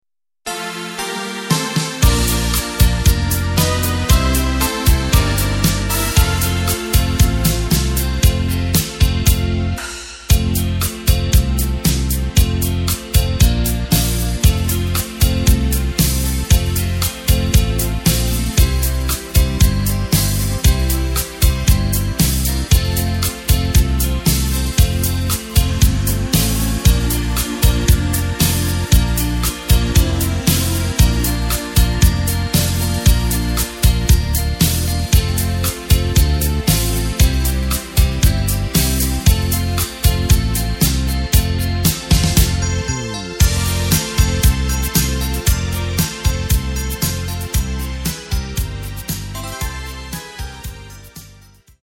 Takt:          4/4
Tempo:         116.00
Tonart:            F
Schlager aus dem Jahr 2006!
Playback mp3 Demo